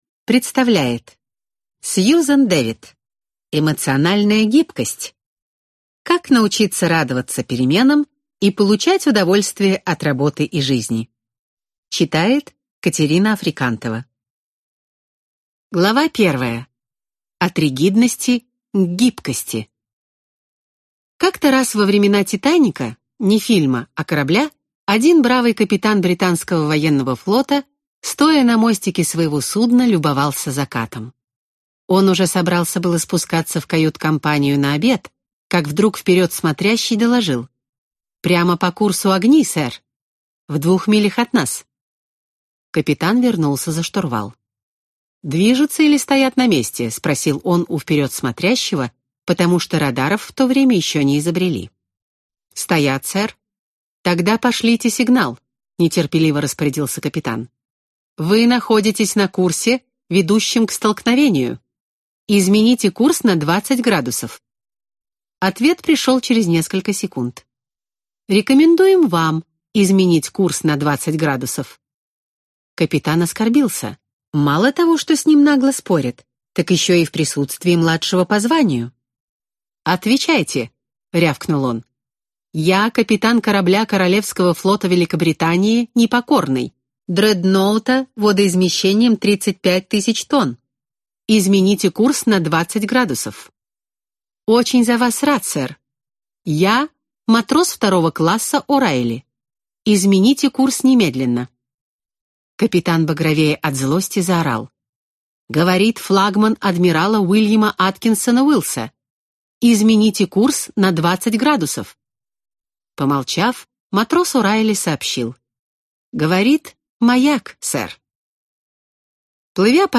Аудиокнига Эмоциональная гибкость | Библиотека аудиокниг